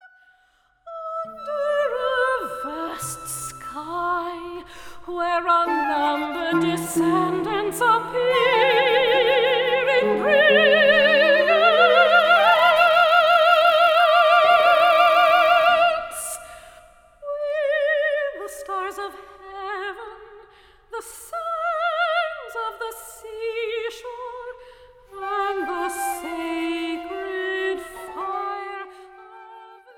instruments, and chorus.